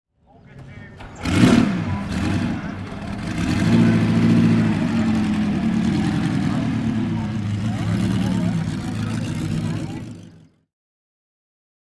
GP Mutschellen 2012 - es muss nicht immer Goodwood sein (Veranstaltungen)
Bugatti T 59 (1934) - Starten
Bugatti_57.mp3